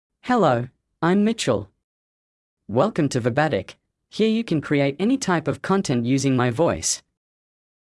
Mitchell — Male English (New Zealand) AI Voice | TTS, Voice Cloning & Video | Verbatik AI
MaleEnglish (New Zealand)
Mitchell is a male AI voice for English (New Zealand).
Voice sample
Mitchell delivers clear pronunciation with authentic New Zealand English intonation, making your content sound professionally produced.